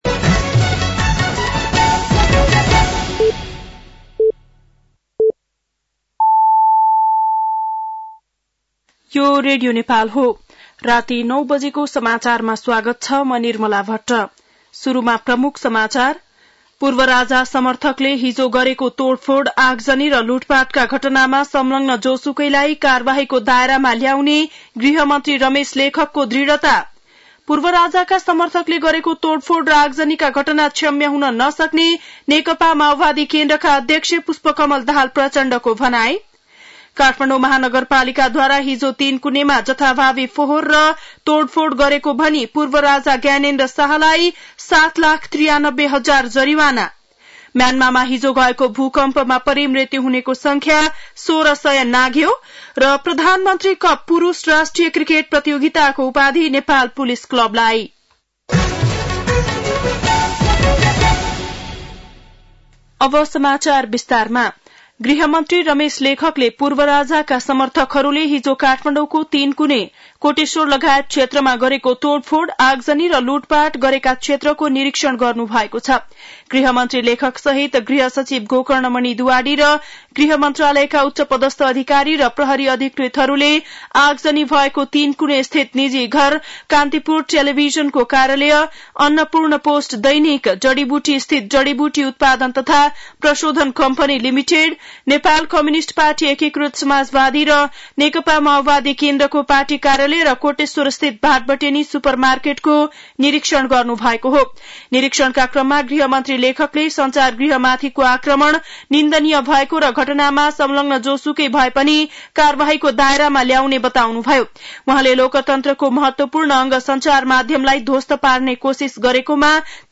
बेलुकी ९ बजेको नेपाली समाचार : १६ चैत , २०८१